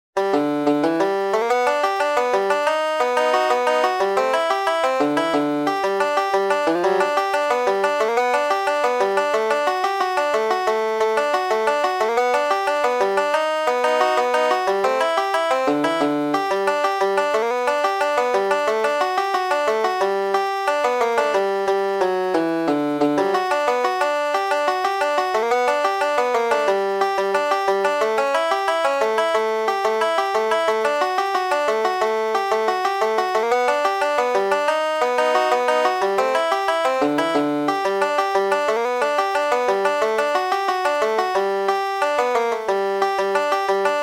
Banjo Lessons